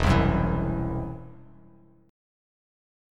Esus2#5 chord